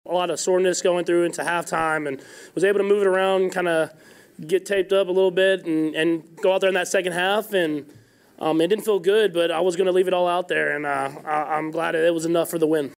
Chiefs quarterback Patrick Mahomes talks about re-aggravating his ankle injury.